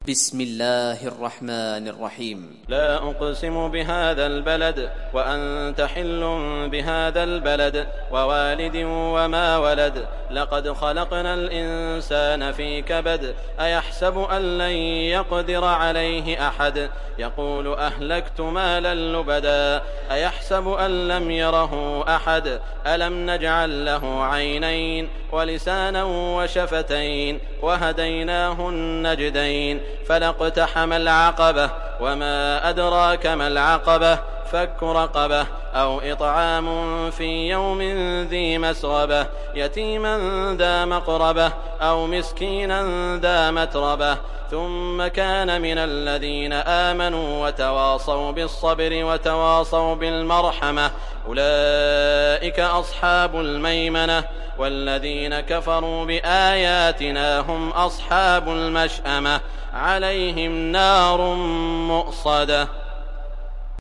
Beled Suresi mp3 İndir Saud Al Shuraim (Riwayat Hafs)
Beled Suresi İndir mp3 Saud Al Shuraim Riwayat Hafs an Asim, Kurani indirin ve mp3 tam doğrudan bağlantılar dinle